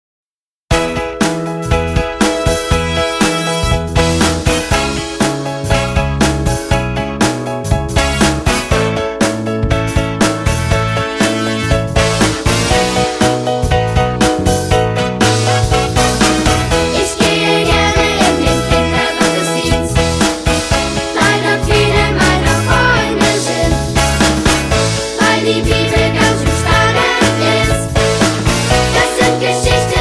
• Sachgebiet: Kinderlieder